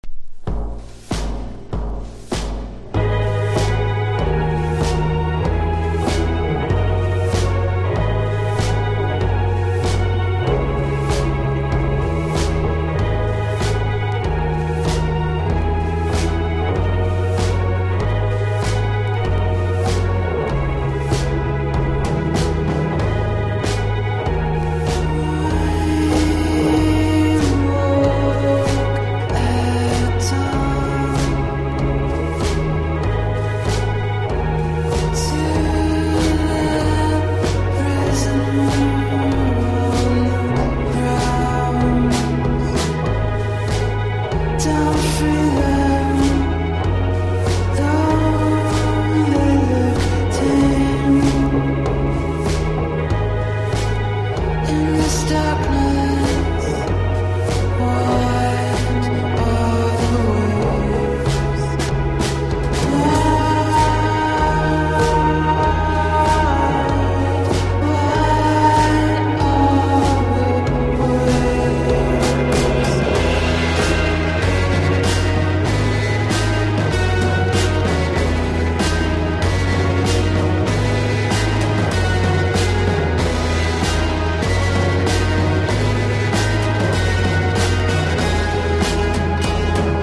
NEO ACOUSTIC / GUITAR POP